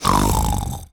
pgs/Assets/Audio/Animal_Impersonations/pig_sniff_long_01.wav at master
pig_sniff_long_01.wav